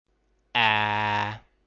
Lelkes próbálkozóknak: á-ra tátjuk a szánk, de ilyen állásban mondunk e-t. Egyes tájszólásokban ejtenek ilyen hangot.
Hangminta (nem pontos, az angol változathoz készült)